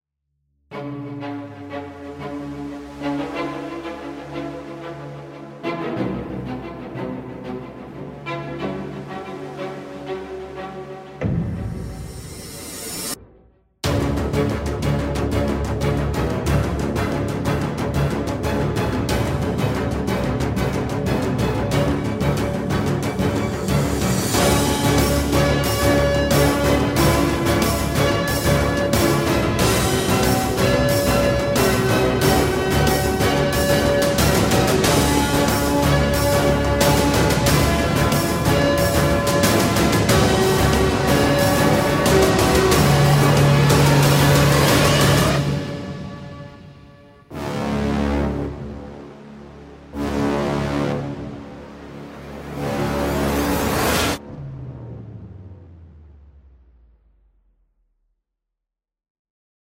Action epic track for trailers.
Action epic track for trailers and sports.